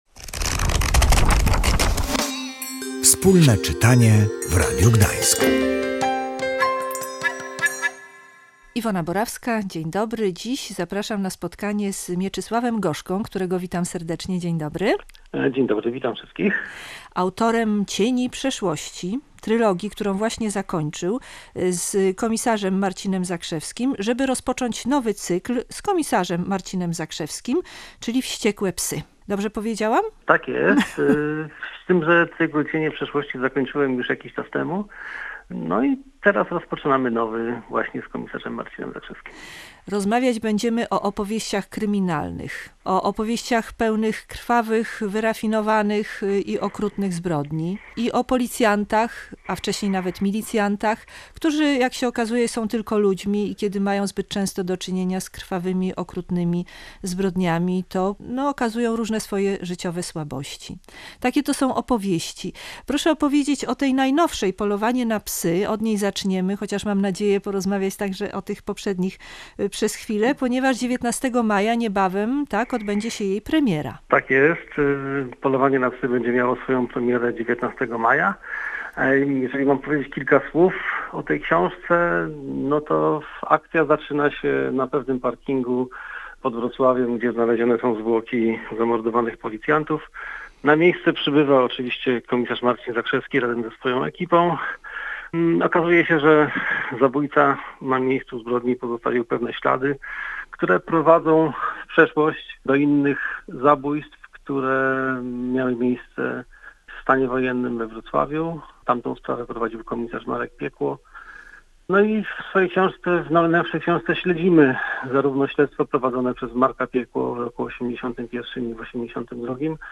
W audycji rozmawiamy o nim z autorem, który, pozostając wiernym swojemu bohaterowi, komisarzowi Marcinowi Zakrzewskiemu, powołał do życia jego mroczne alter ego z przeszłości.